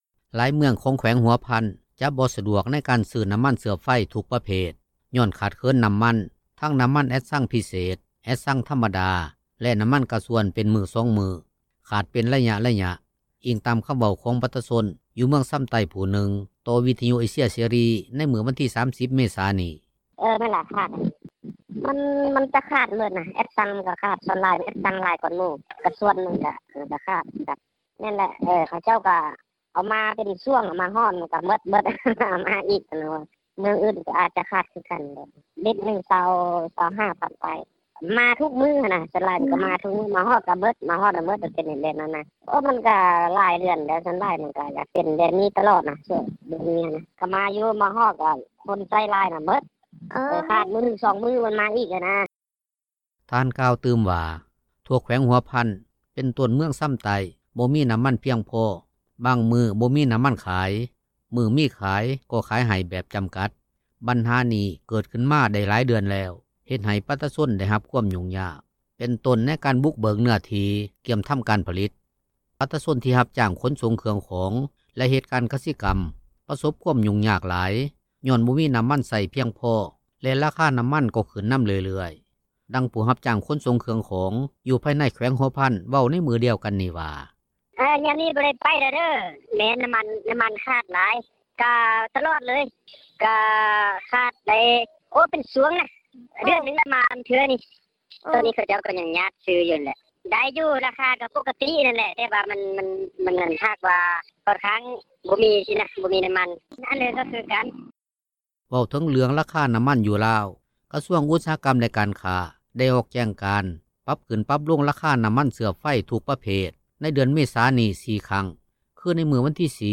ຫລາຍເມືອງ ຂອງແຂວງຫົວພັນ ກຳລັງປະສົບບັນຫາ ໃນການຫາຊື້ນໍ້າມັນເຊື້ອໄຟ ທຸກປະເພດ ຍ້ອນຂາດເຂີນນໍ້າມັນ ເປັນມື້ສອງມື້, ຂາດເປັນໄລຍະໆ, ອີງຕາມຄໍາເວົ້າຂອງປະຊາຊົນ ຢູ່ເມືອງຊໍາໃຕ້ຜູ້ນຶ່ງ ຕໍ່ວິທຍຸເອເຊັຍເສຣີ ໃນມື້ວັນທີ 30 ເມສານີ້ວ່າ: